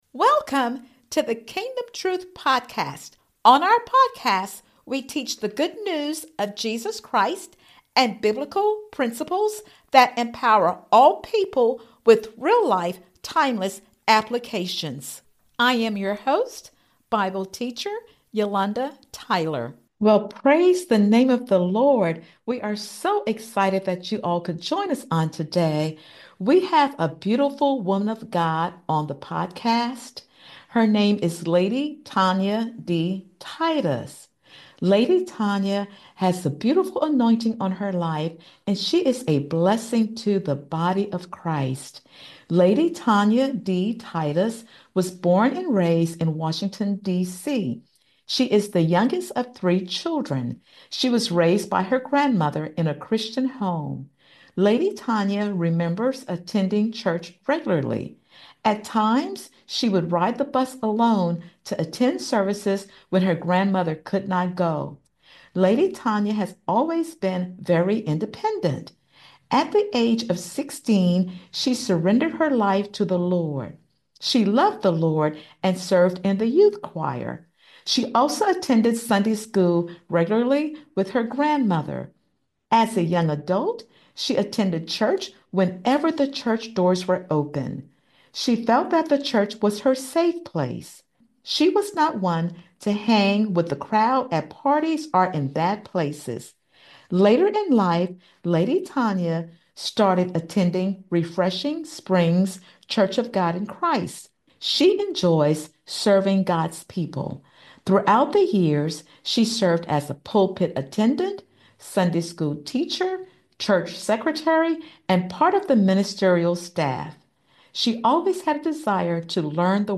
A Pastor's Wife Testimony